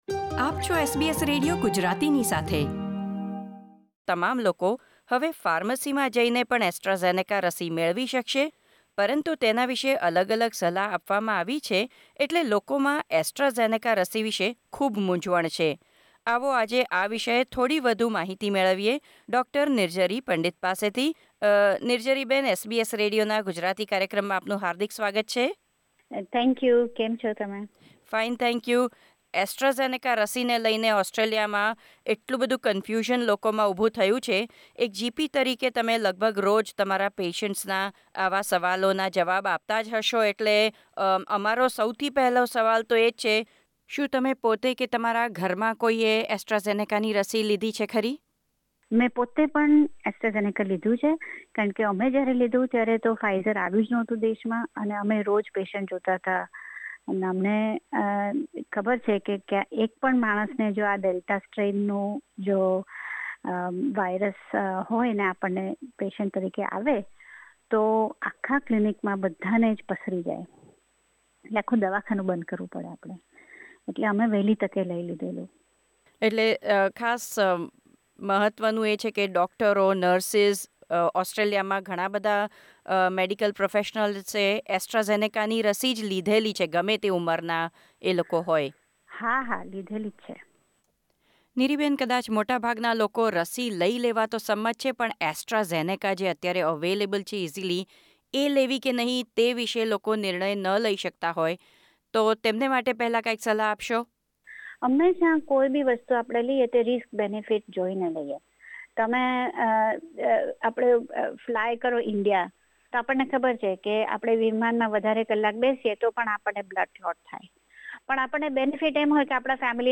How can you decide whether to take AstraZeneca, GP explains.